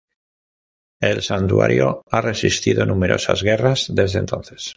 san‧tua‧rio
/sanˈtwaɾjo/